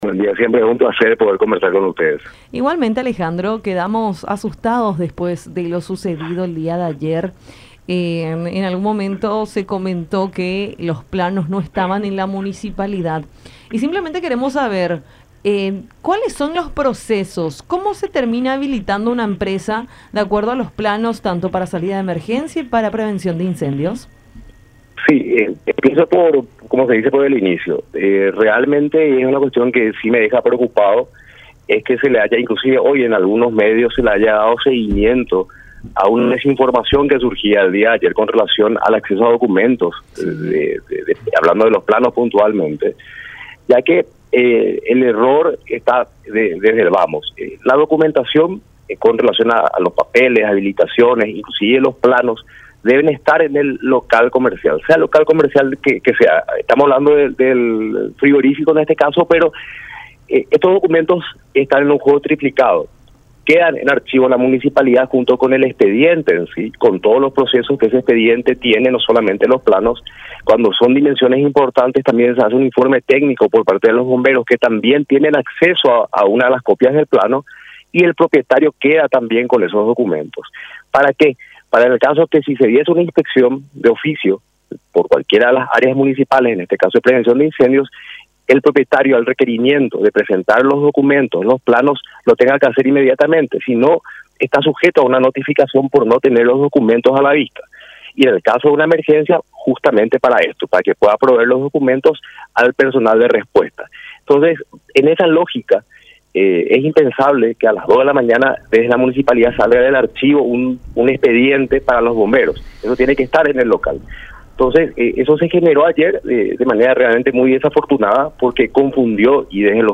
en conversación con Enfoque 800 por La Unión.